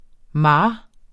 Udtale [ ˈmɑː ]